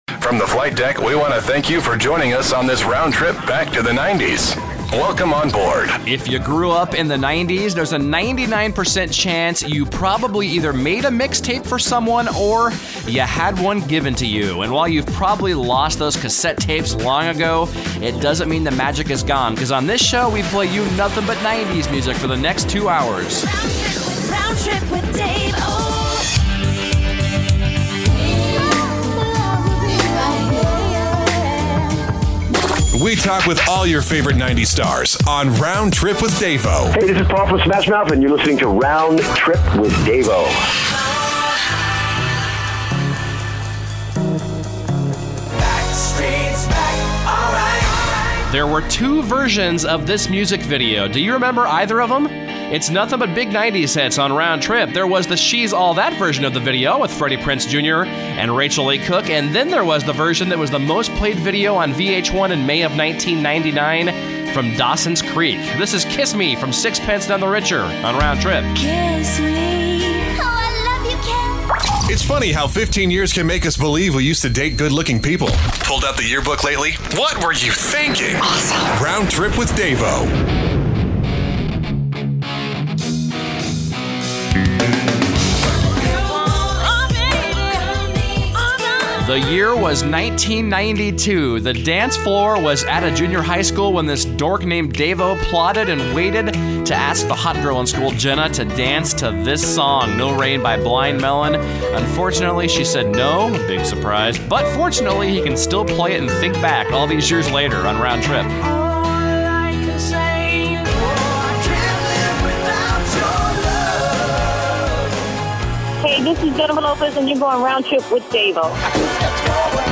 The show is fully imaged with a jingle package (the old Blink in New York City) and two voice professionals.